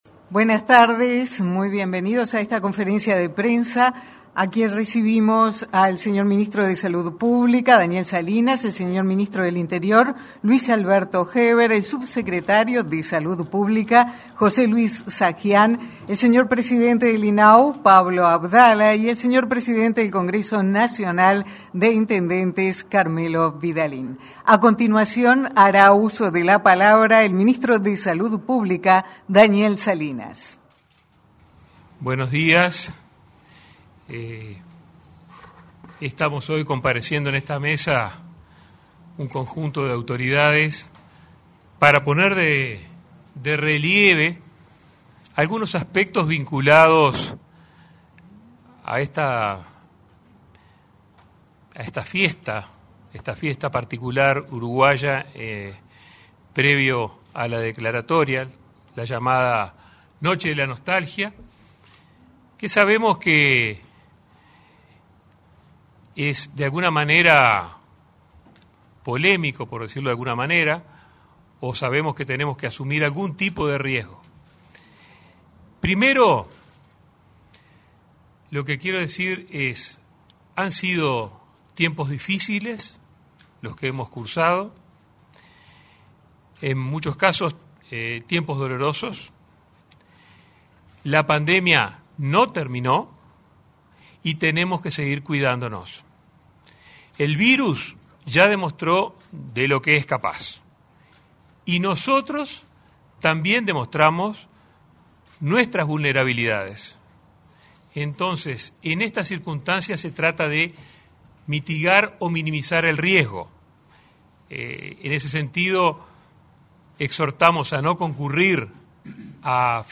Conferencia por operativo Noche de la Nostalgia
Conferencia por operativo Noche de la Nostalgia 23/08/2021 Compartir Facebook X Copiar enlace WhatsApp LinkedIn Este lunes 23, se realizó una conferencia de prensa en Torre Ejecutiva, para informar sobre el operativo de seguridad con motivo de la Noche de la Nostalgia. Participaron el ministro del Interior, Luis Alberto Heber, el ministro de Salud Pública, Daniel Salinas, el subsecretario, José Luis Satdjian, autoridades del Congreso de Intendentes y del INAU.